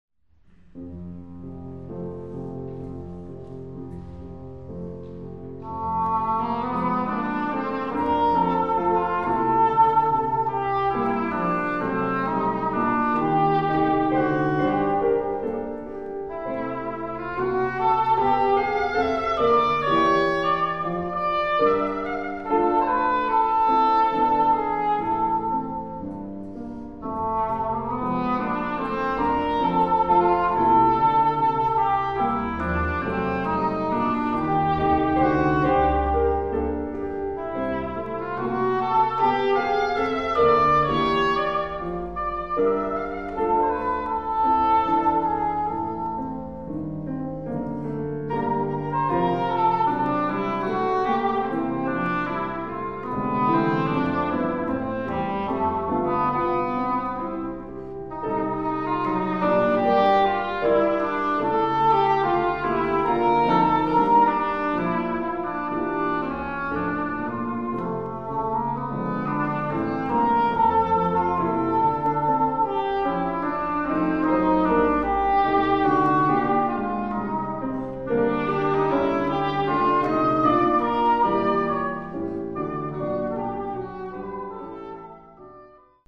• Felix Draeseke: aus der "kleinen Suite" für Englisch Horn op. 87
erster Satz
Klavier